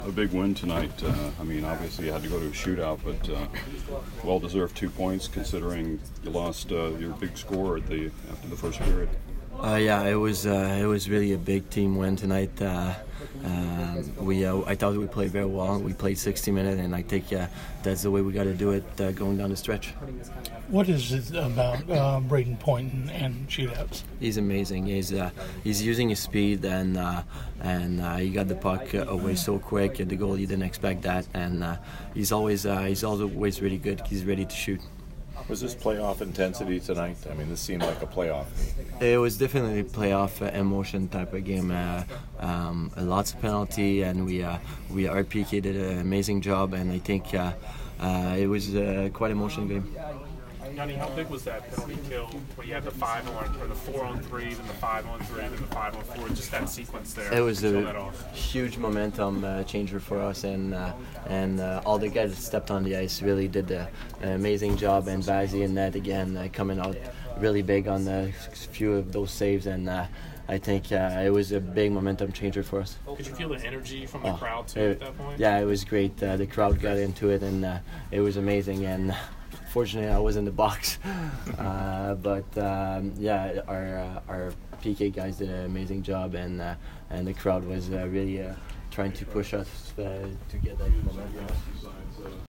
Yanni Gourde post-game 2/26